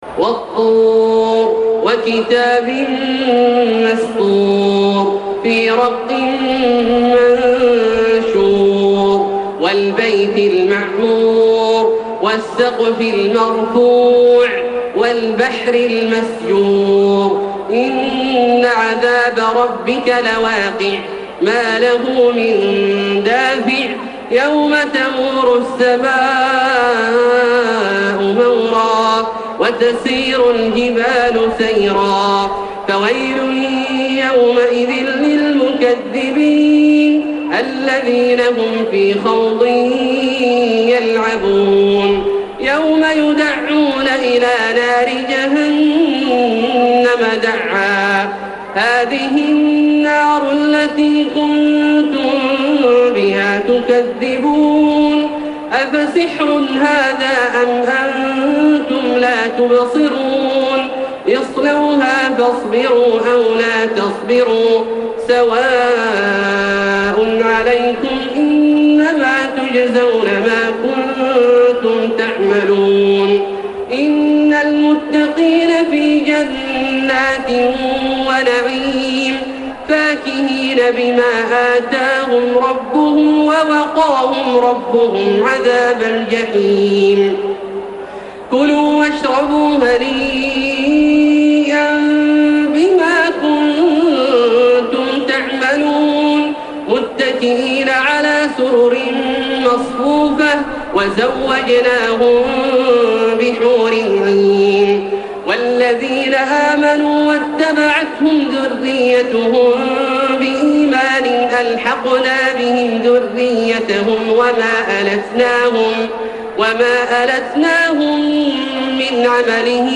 Surah At-Tur MP3 by Makkah Taraweeh 1432 in Hafs An Asim narration.
Murattal